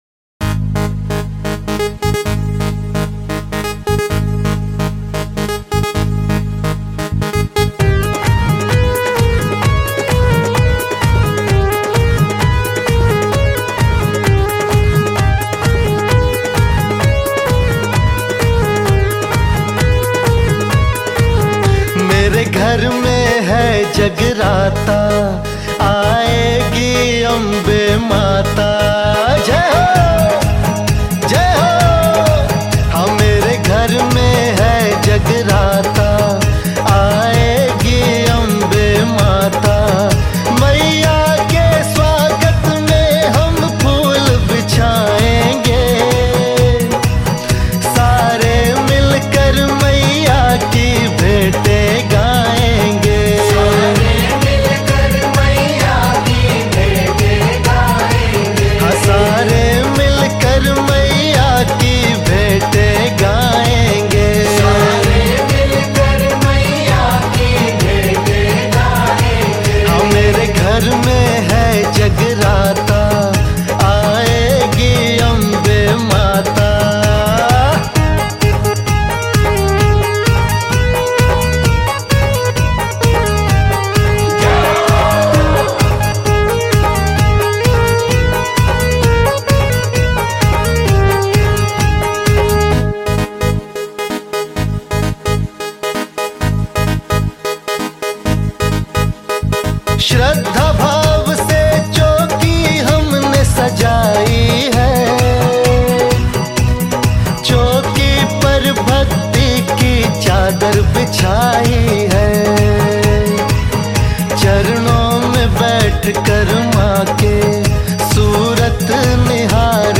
Mata Bhajan
Hindi Bhajan